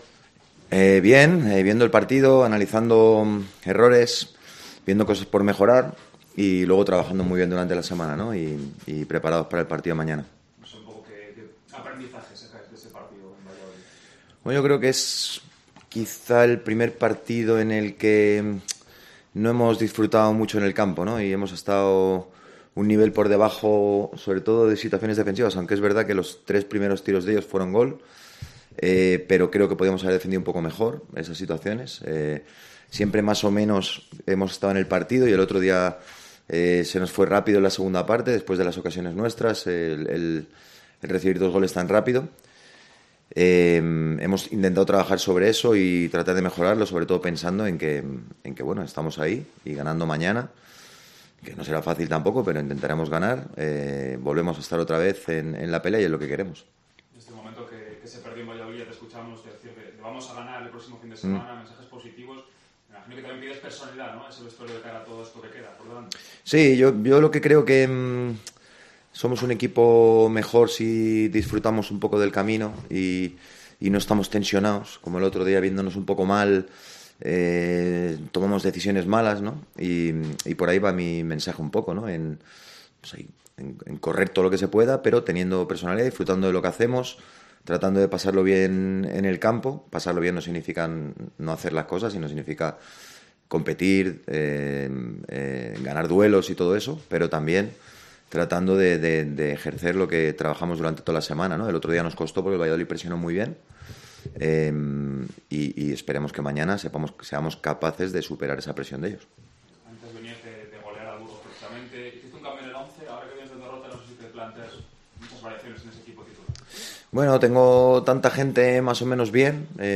Este viernes ha hablado Luis Carrión en sala de prensa.